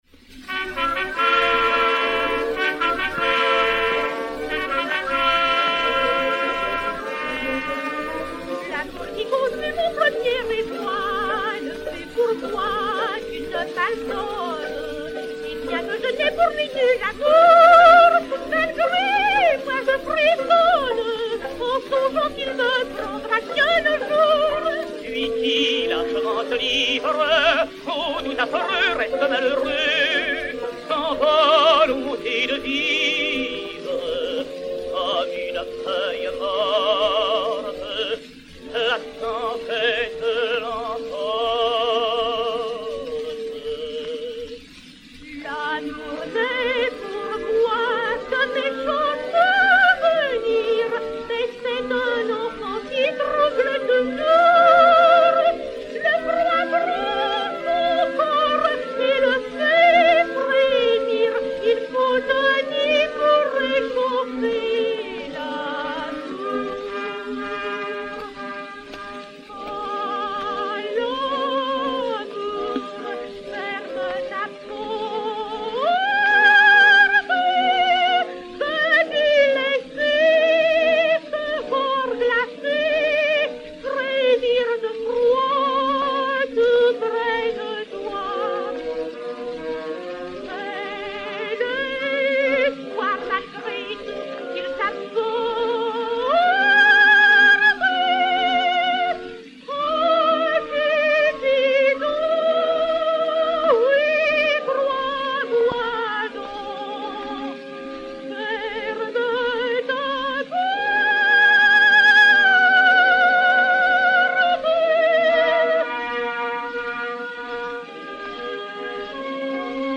soprano français
Duo